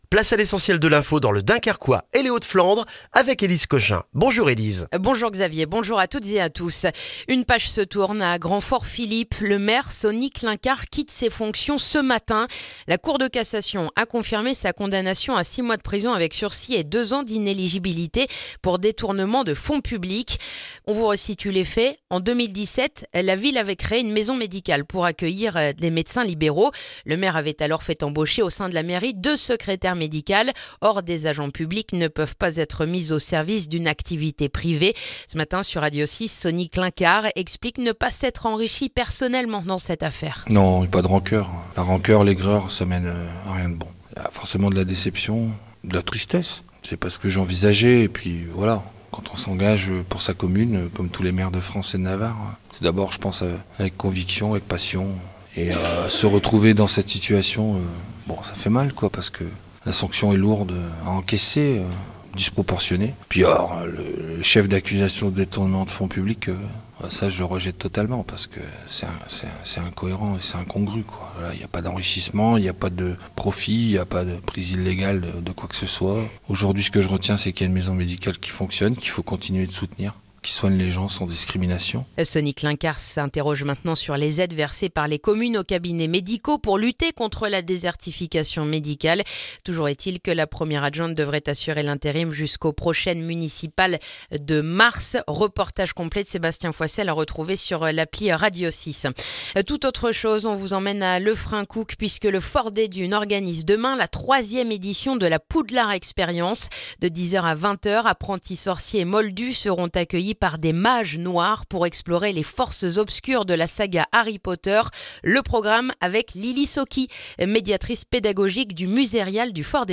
Le journal du vendredi 6 février dans le dunkerquois